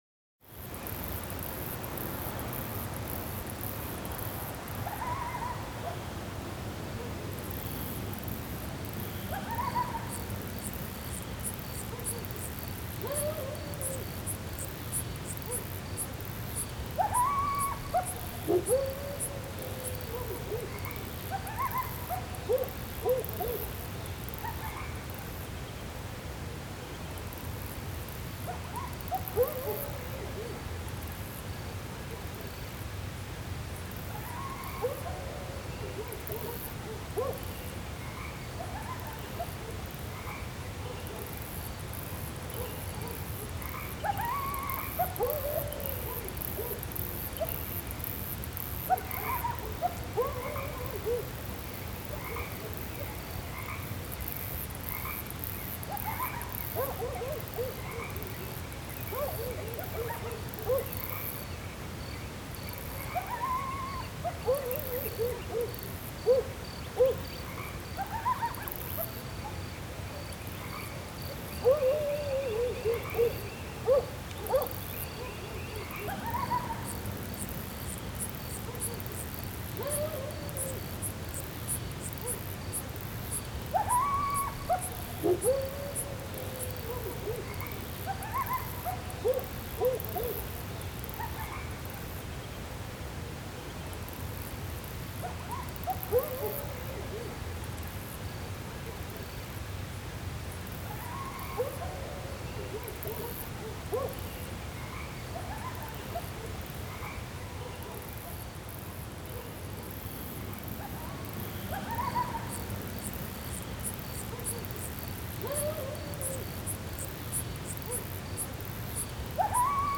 AMB_Scene04_River_Calm.ogg